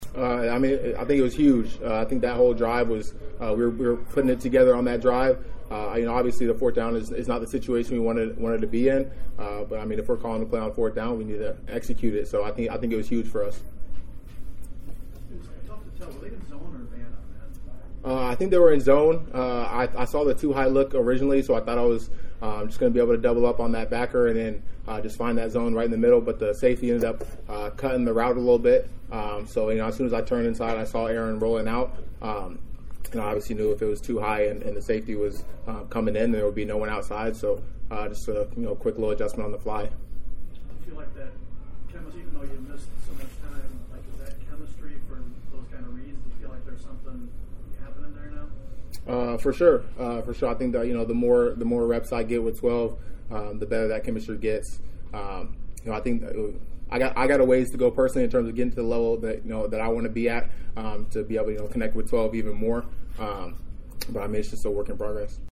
The former North Dakota State speedster has now piled up 9 touchdowns this season, two on the ground and 7 through the air and he was ushered into the post-game interview room to answer questions, beginning with his big scoring grab on 4th down right before the half: